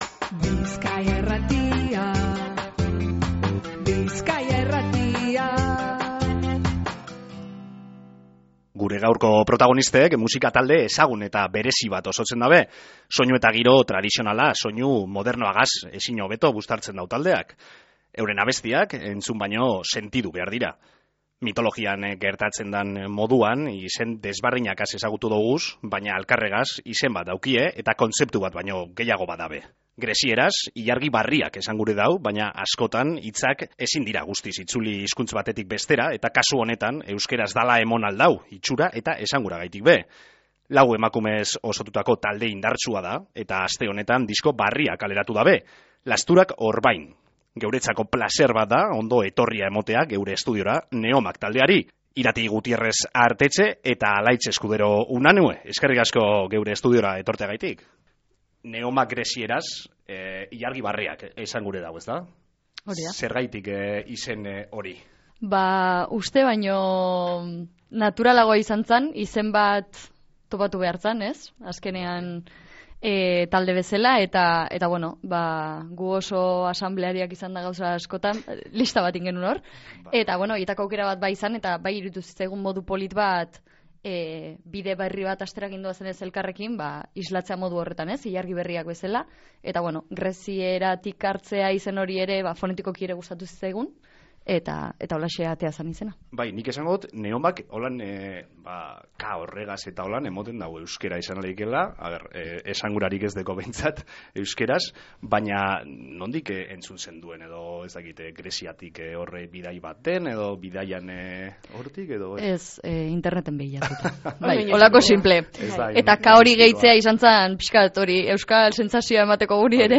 gure estudioan euki doguz